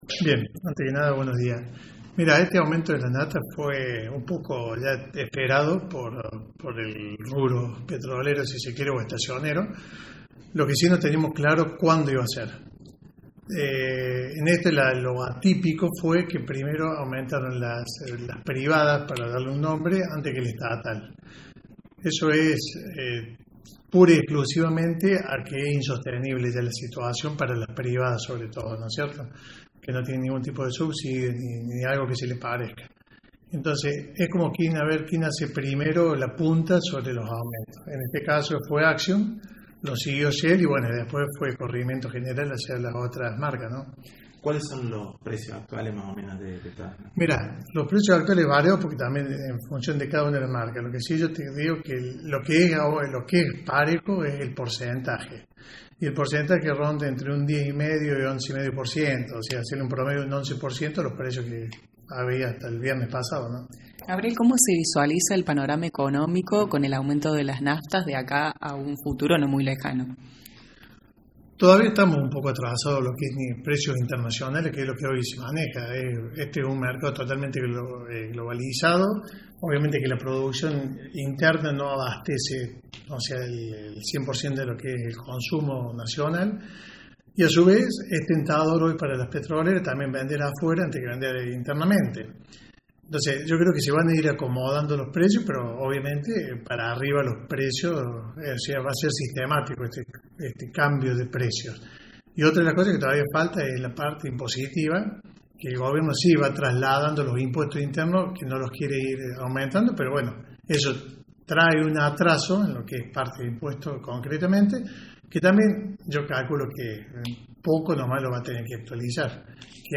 Dialogamos con el encargado de una estación de servicios de la vecina Villa Nueva quienes nos amplía sobre los nuevos valores.